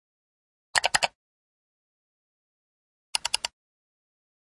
Cạch âm thanh phát ra sau khi ấn nút Enter trên máy tính thông báo thông tin sẽ được xử lý trong thời gian ngắn nhất. Xin vui lòng click vào link dưới đây để tải về nếu bạn cần âm thanh cho việc dựng phim nhé. computer sound button press sound keyboard sound